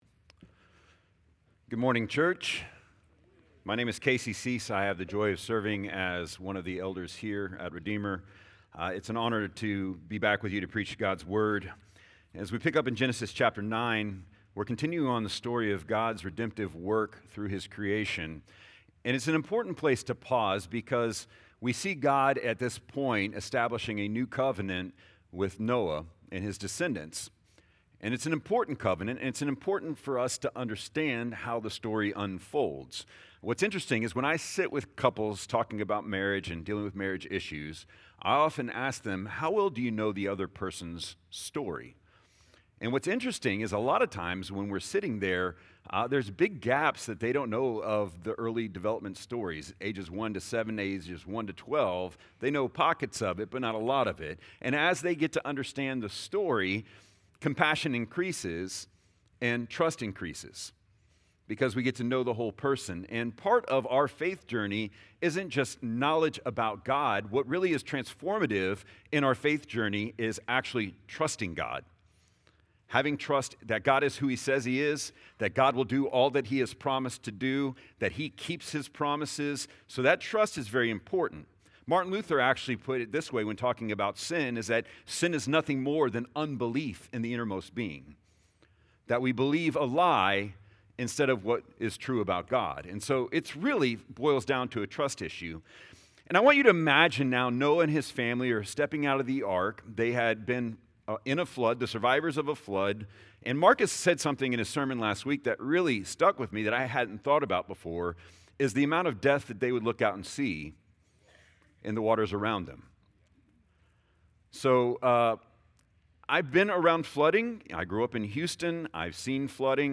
Genesis Category: Sermons